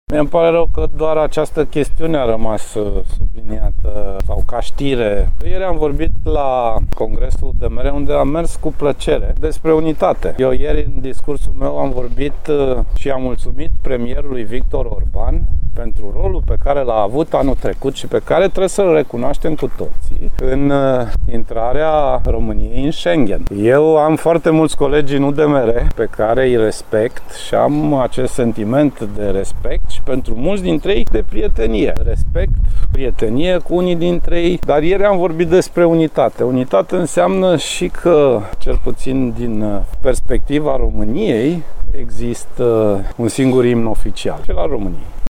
Prezent, astăzi, la Timișoara, el a declarat că în ţară există un singur imn oficial, cel al României.